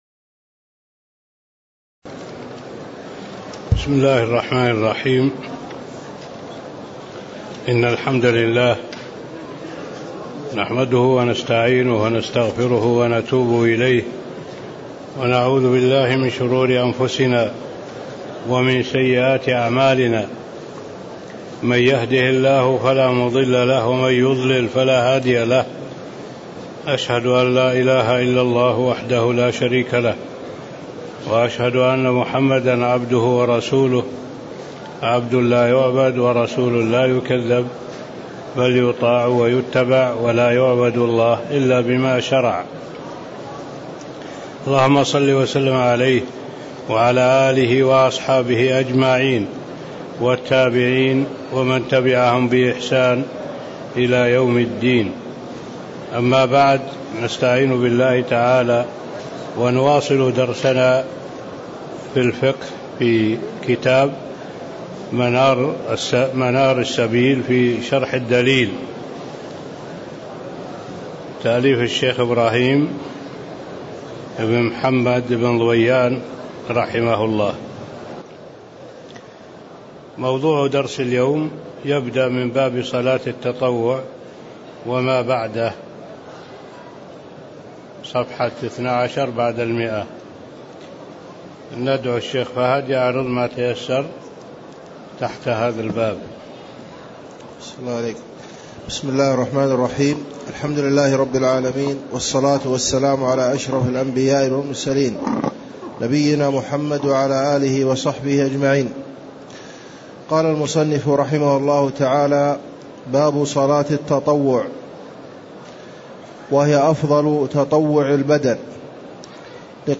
تاريخ النشر ١ رجب ١٤٣٦ هـ المكان: المسجد النبوي الشيخ